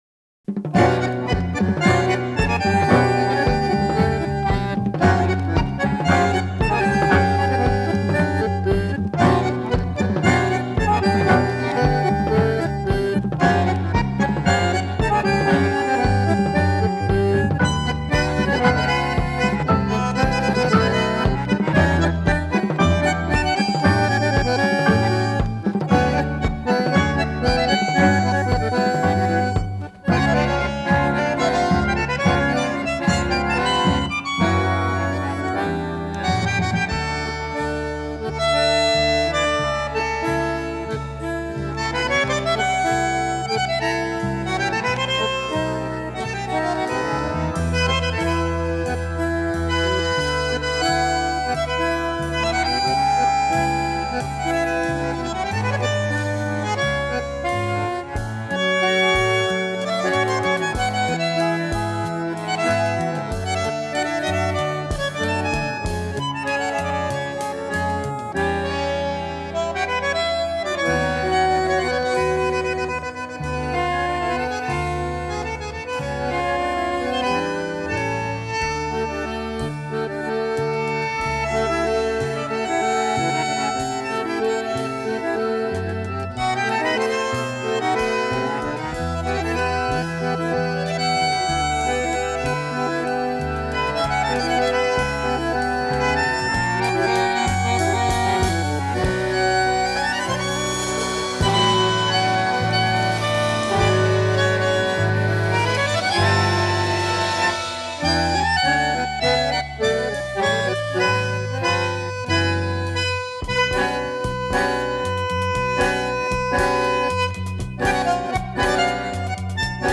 Танго
bandoneon
Recorded in Milan, Italyin May 1974